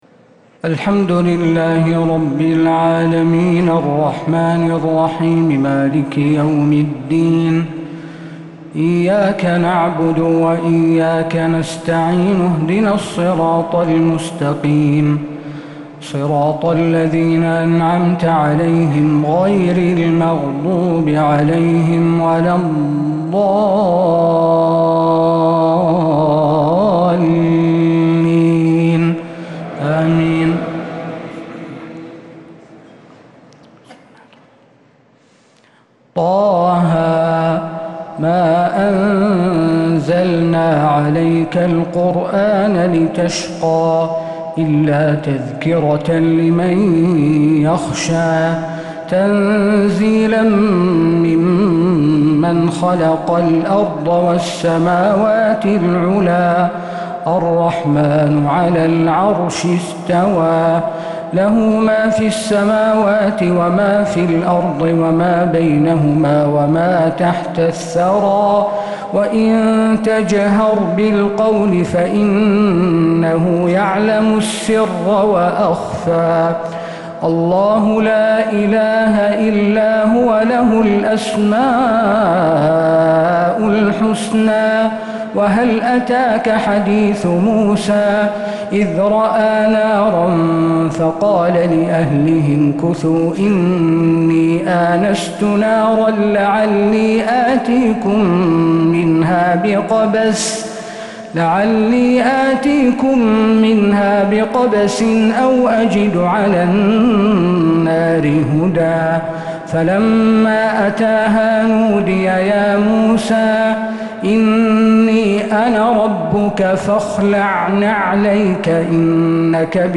تراويح ليلة 21 رمضان 1446 فواتح سورة طه (1-98) | Taraweeh 21st night Ramadan 1446H Surah TaHa > تراويح الحرم النبوي عام 1446 🕌 > التراويح - تلاوات الحرمين